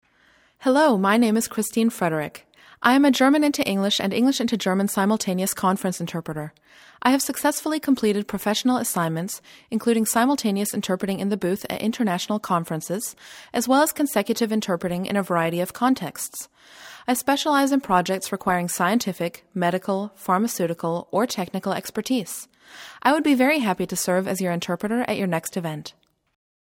The links below are voice samples for you to ascertain for yourself the accent free nature of my voice.
Greeting Vorstellung